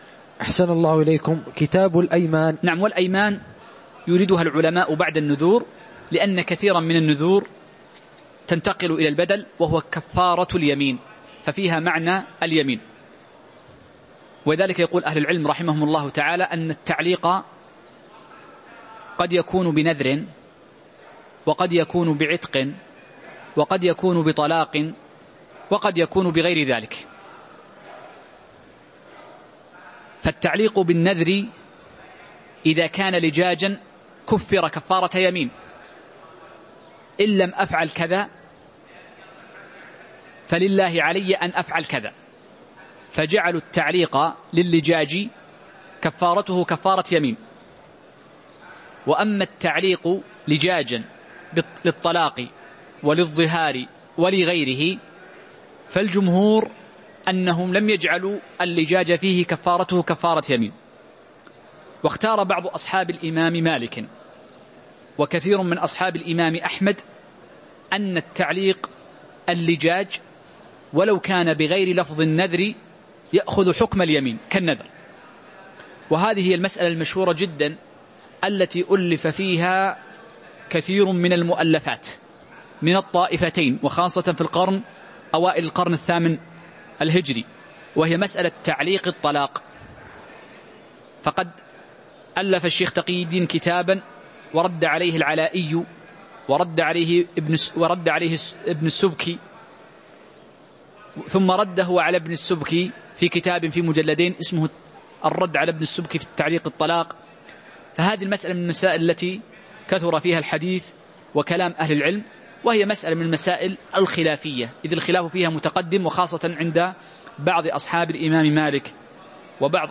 تاريخ النشر ١٢ ربيع الأول ١٤٤١ هـ المكان: المسجد النبوي الشيخ